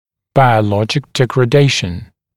[ˌbaɪəu’lɔʤɪk ˌdegrə’deɪʃn][ˌбайоу’лоджик ˌдэгрэ’дэйшн]ухудшение или разрушение материалов под воздействием биологических факторов